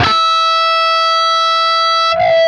LEAD E 4 CUT.wav